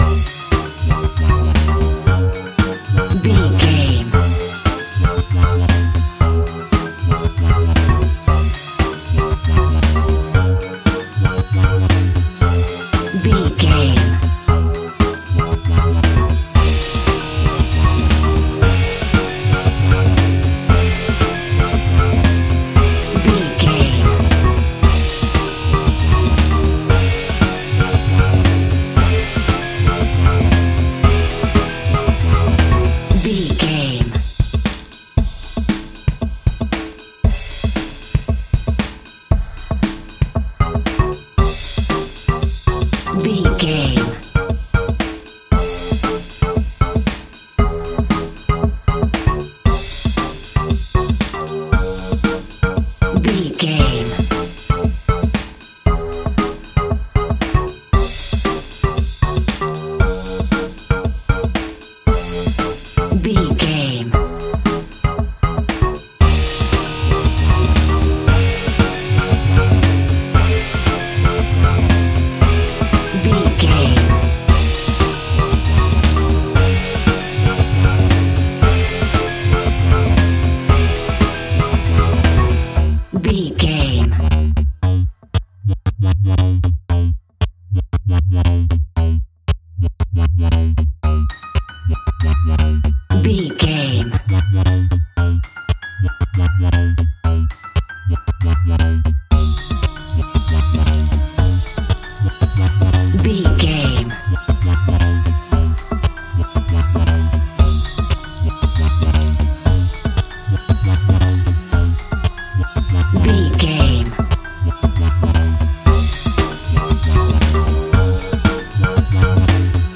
Gangster Club Music.
Atonal
Funk
drums
bass guitar
electric guitar
piano
hammond organ
percussion